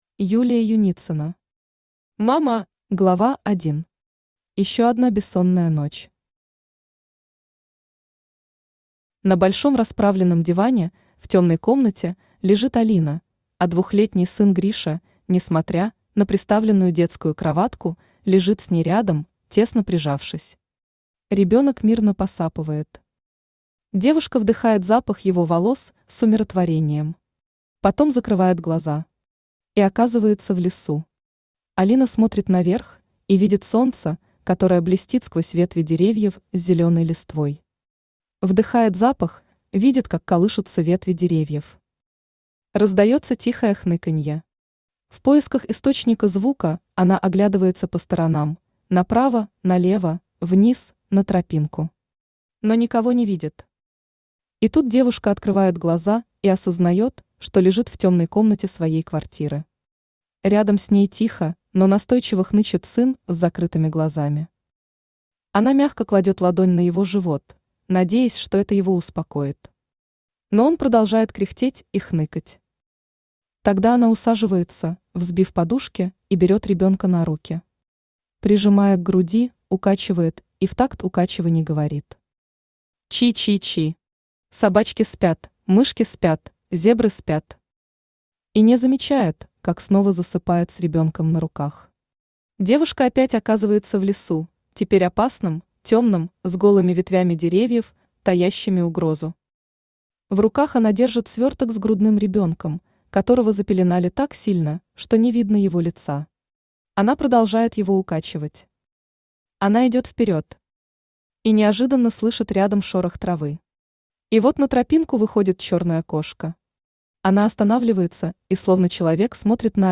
Аудиокнига Ма-ма!
Автор Юлия Юницина Читает аудиокнигу Авточтец ЛитРес.